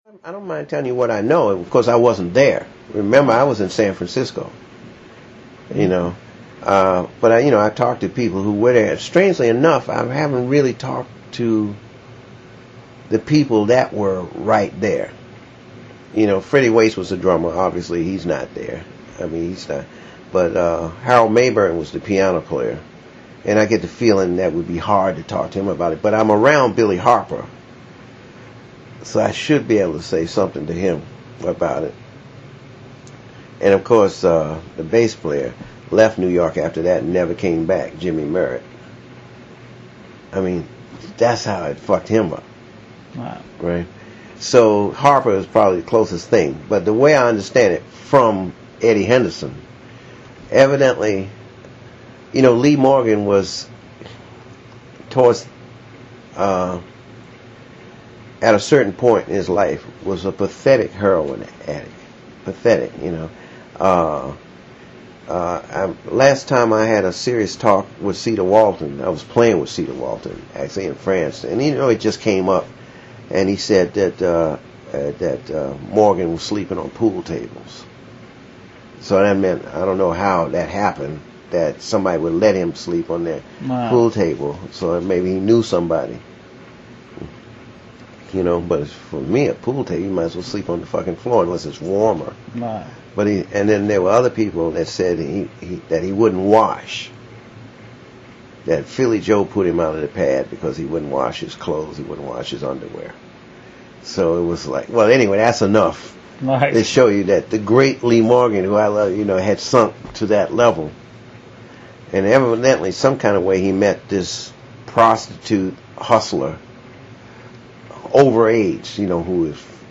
Interview with Billy Hart